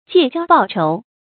借交報仇 注音： ㄐㄧㄝ ˋ ㄐㄧㄠ ㄅㄠˋ ㄔㄡˊ 讀音讀法： 意思解釋： 幫助別人報仇。